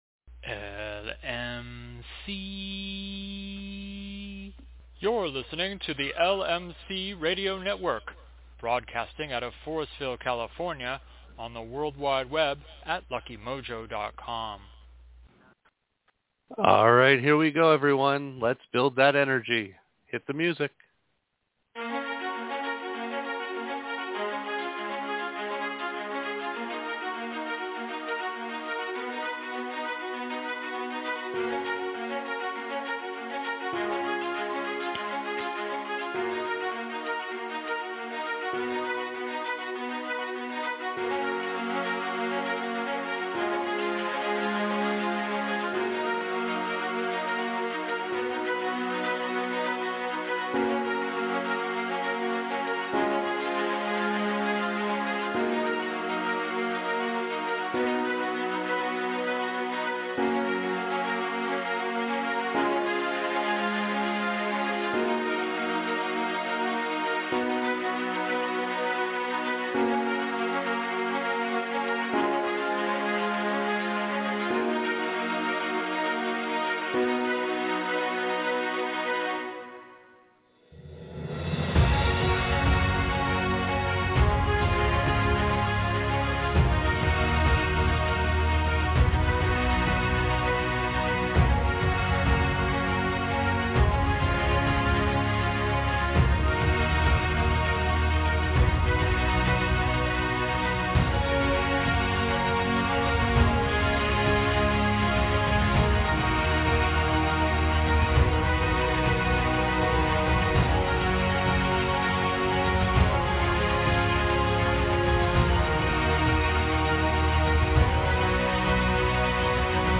We begin this show with an update from our guest followed by a discussion on the Doctrine of Signatures in magical work. So, what IS the Doctrine of Signatures?